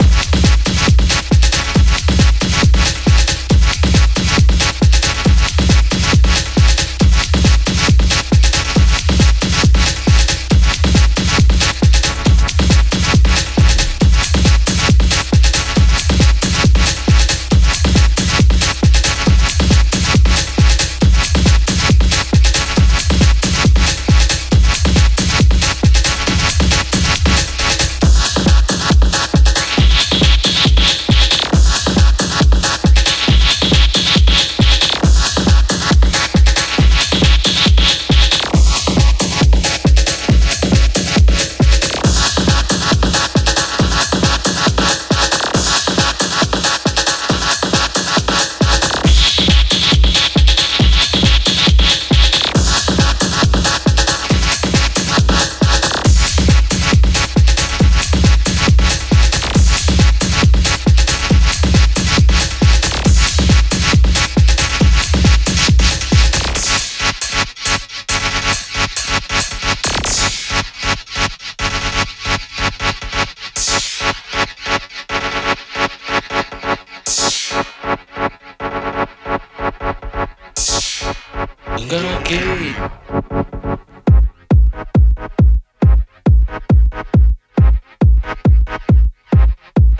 ジャンル(スタイル) DEEP HOUSE / HOUSE / TECHNO